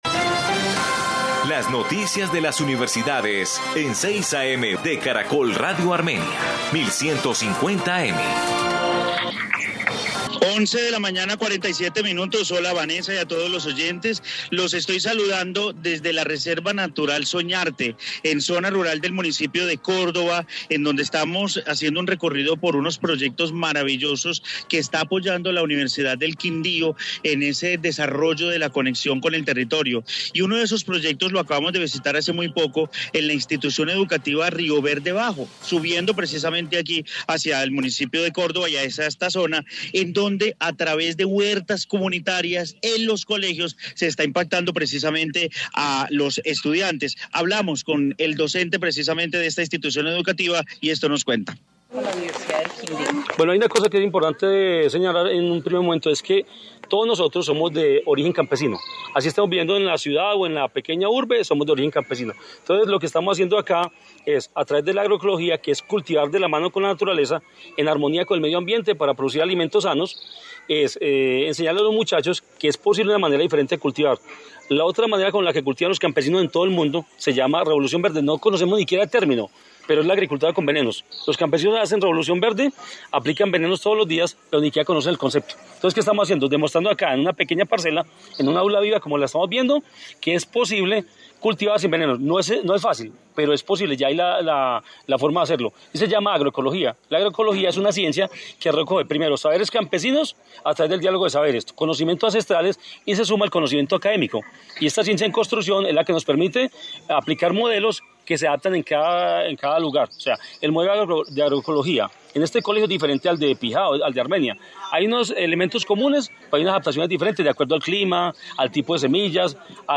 Informe huertas escolares en Quindío